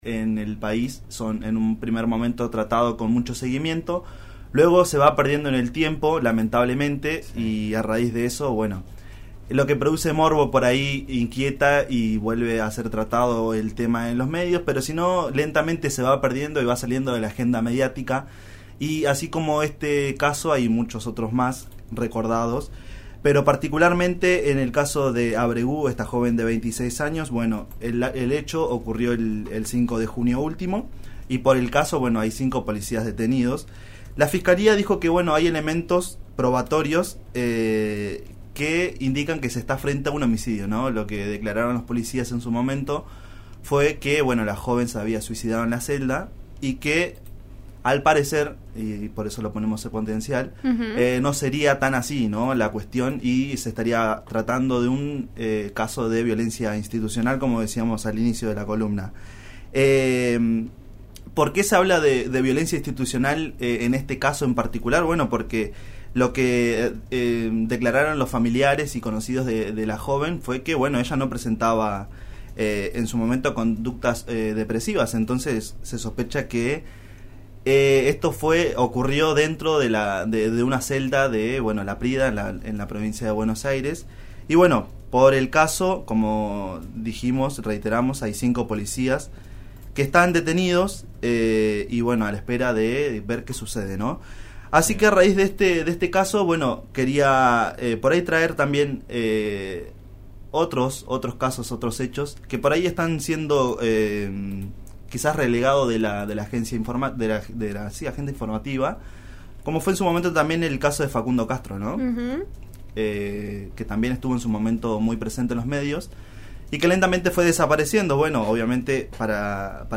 Escuchá la columna de RN Radio.